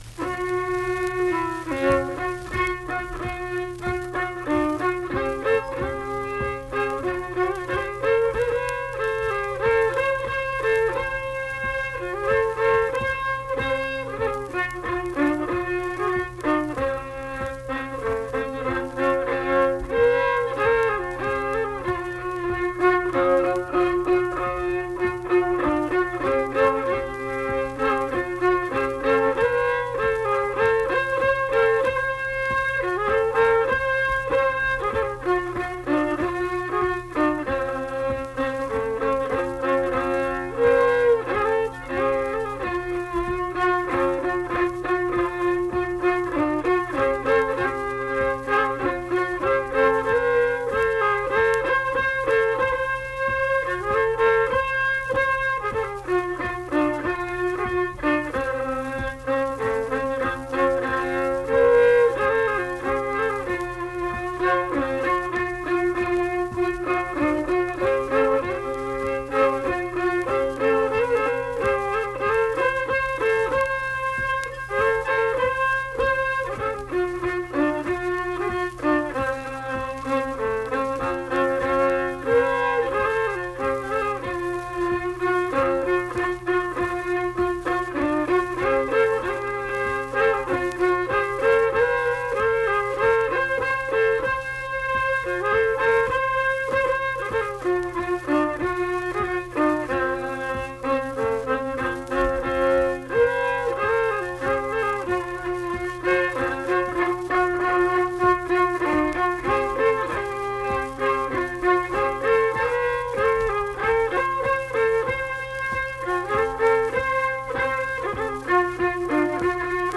Unaccompanied fiddle and vocal music performance
Verse-refrain 8(1).
Instrumental Music
Fiddle